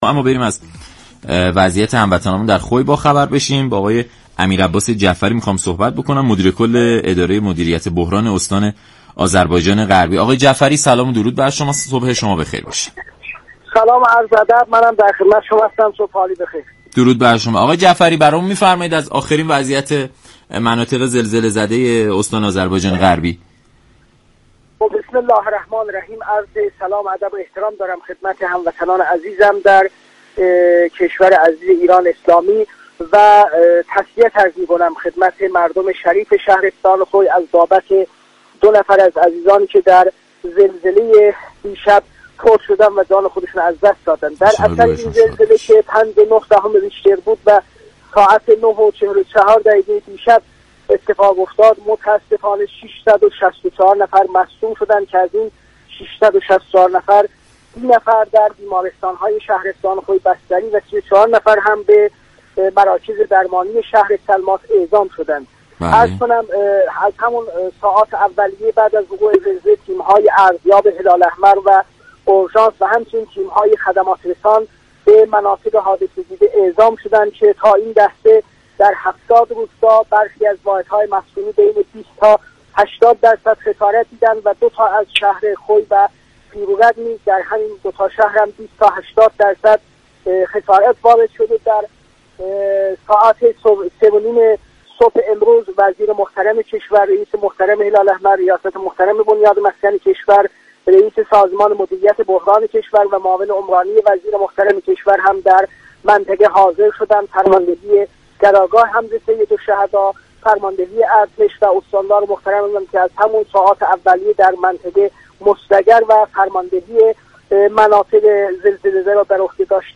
مدیر كل اداره مدیریت بحران استان آذربایجانغربی گفت: تا بدین لحظه برخی از واحدهای مسكونی در 70 روستای شهرستان خوی بین 20 تا 80 درصد دچار خسارات شده اند.